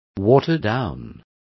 Also find out how aguado is pronounced correctly.